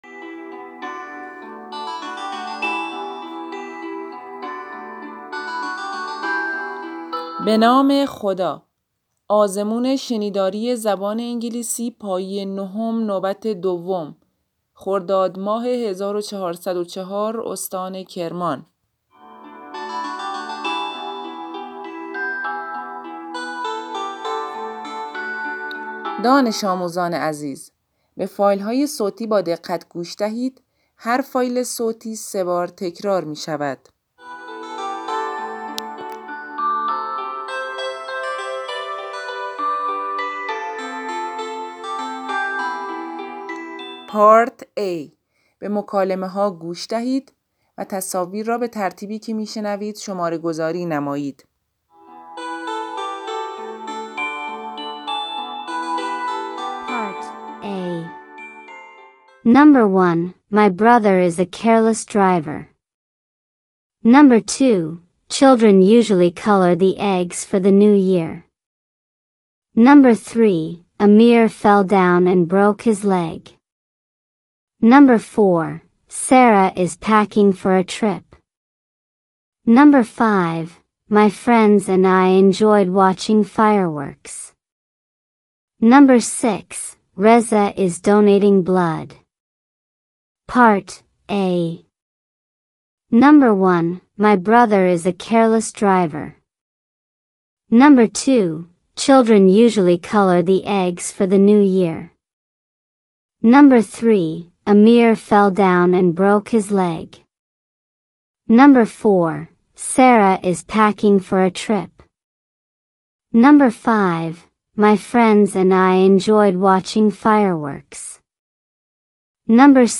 دانلود فایل صوتی آزمون شنیداری (رایگان)